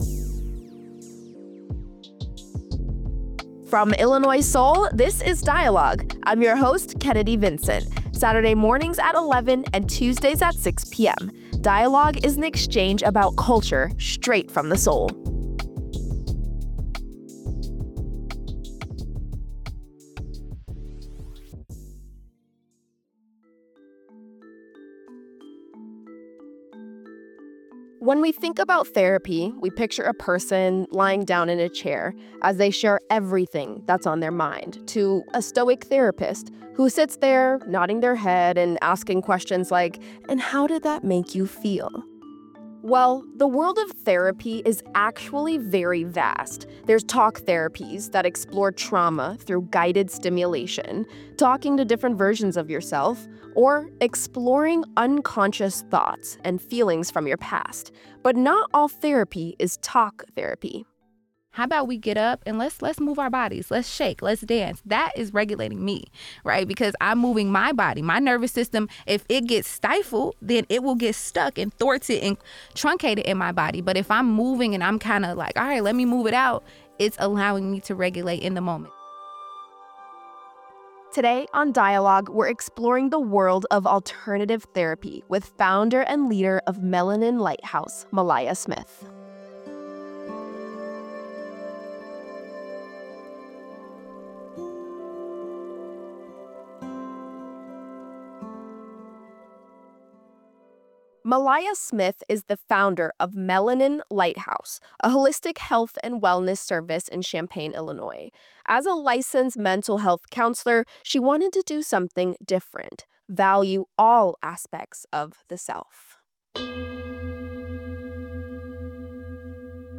The conversation challenges traditional Western therapy models while emphasizing the importance of treating people as “multi-dimensional” beings whose mental, physical, and spiritual health are interconnected.